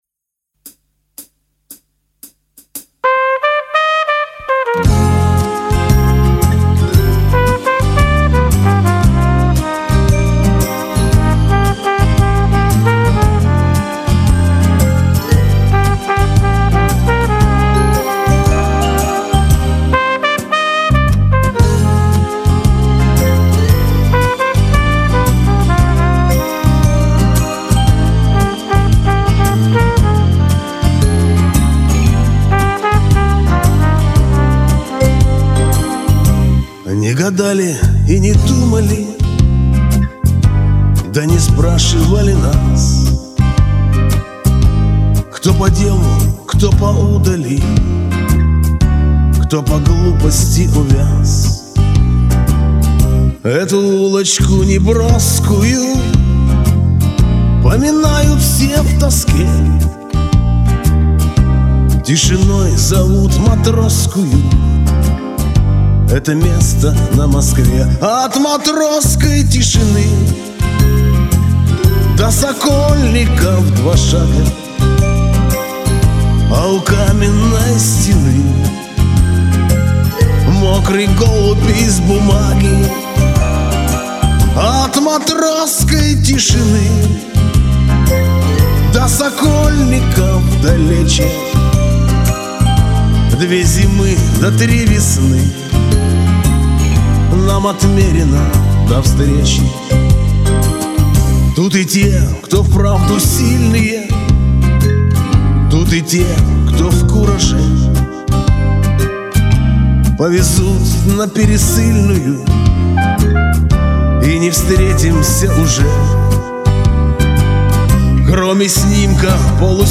напористо
очень щемяще исполнено!))